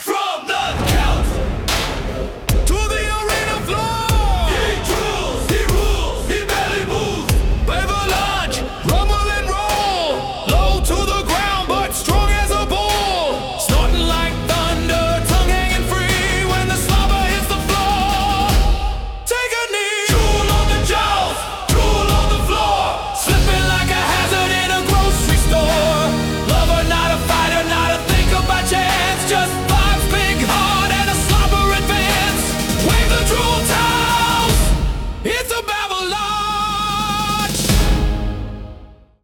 🎶 Theme song